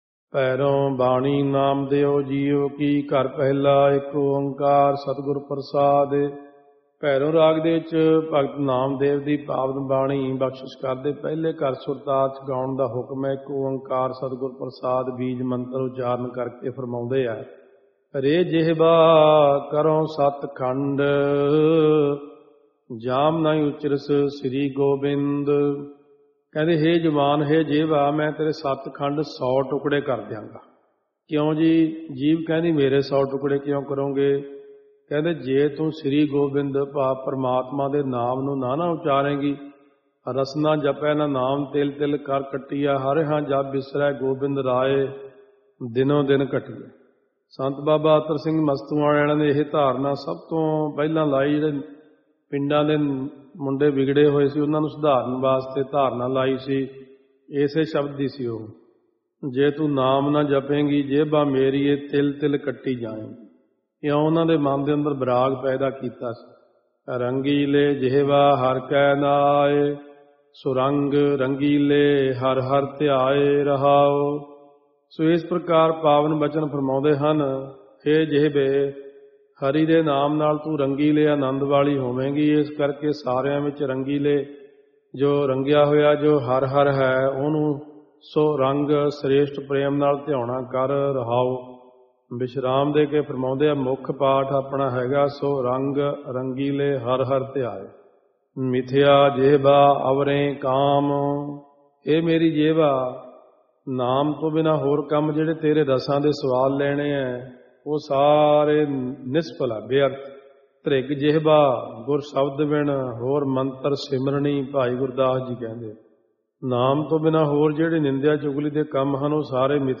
Stream and download soul-soothing Gurbani Katha by Katha Sri Guru Granth Sahib Ji.